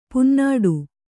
♪ punnāḍu